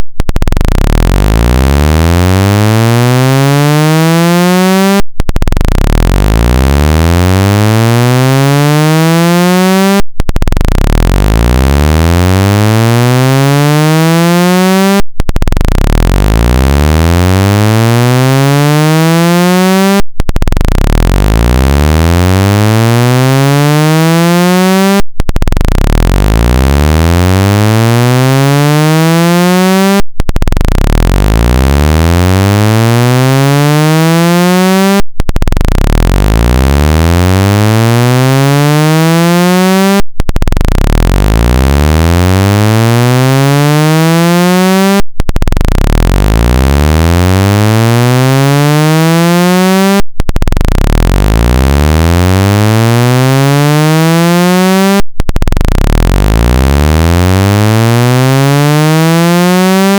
Phát âm thanh đẩy nước khỏi loa iPhone nhanh chóng tại đây (không nên đeo tai nghe để nghe âm thanh):
Sound for Speaker Cleaner 1-200Hz
sound-for-speaker-cleaner-1-200hz.mp3